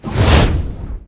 fire3.ogg